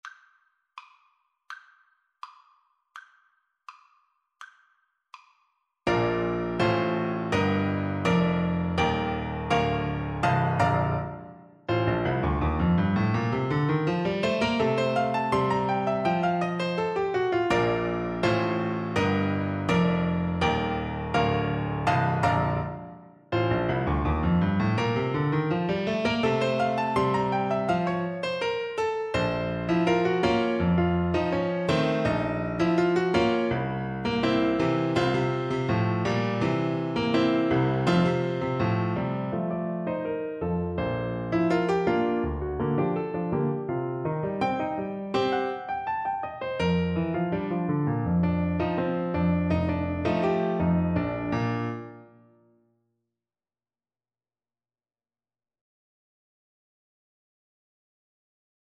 Play (or use space bar on your keyboard) Pause Music Playalong - Piano Accompaniment Playalong Band Accompaniment not yet available transpose reset tempo print settings full screen
Flute
Bb major (Sounding Pitch) (View more Bb major Music for Flute )
2/4 (View more 2/4 Music)
Allegro con energia = c. 110 (View more music marked Allegro)
Classical (View more Classical Flute Music)